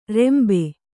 ♪ rembe